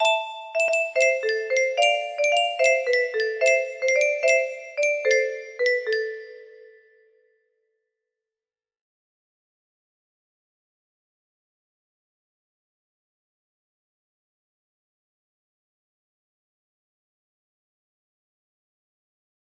Casterruins 1 music box melody